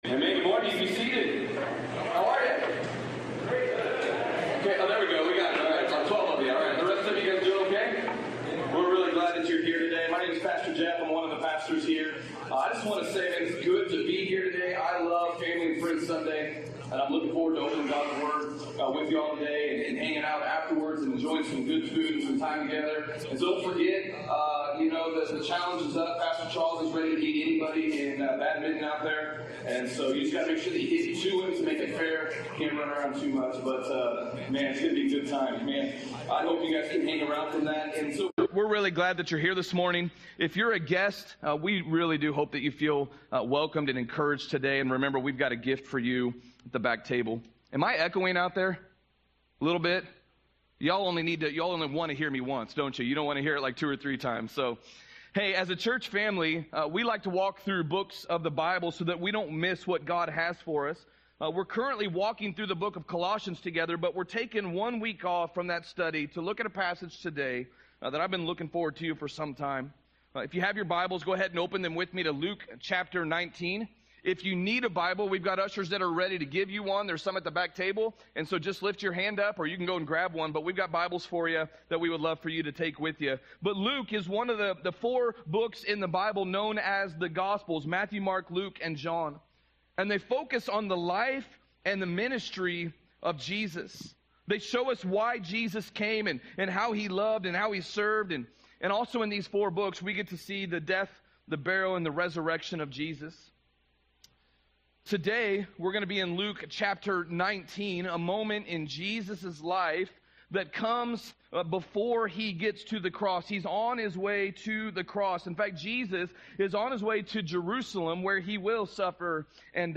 Sermon+9.15.24.mp3